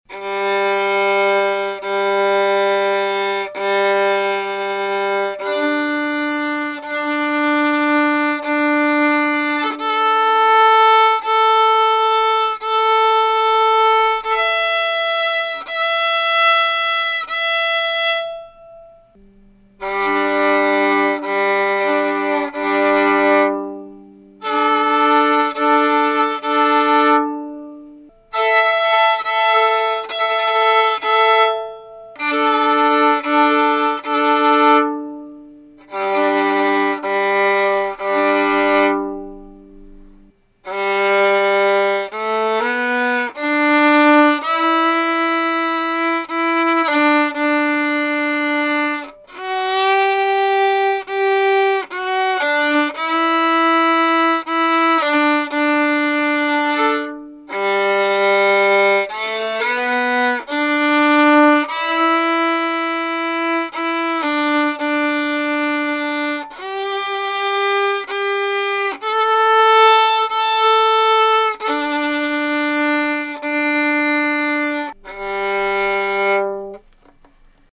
Violin-1-2.mp3